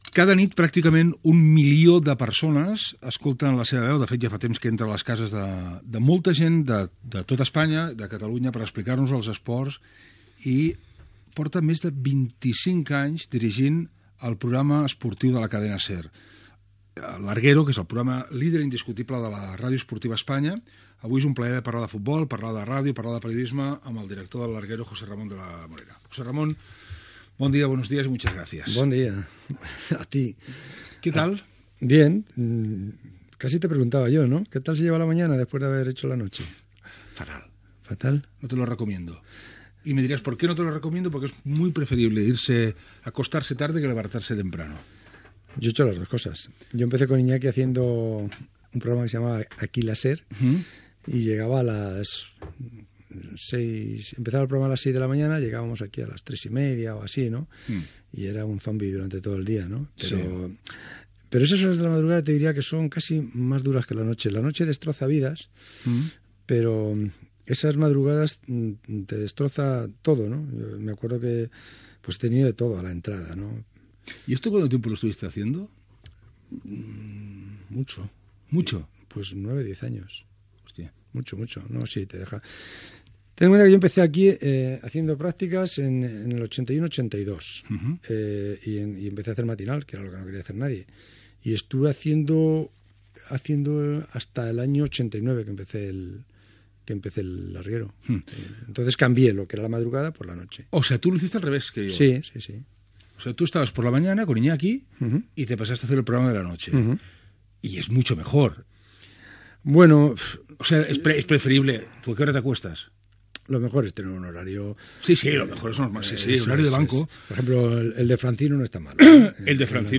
Fragment d'una entrevista al periodista José Ramón de la Morena, presentador de "El larguero" a la Cadena SER.
Info-entreteniment
FM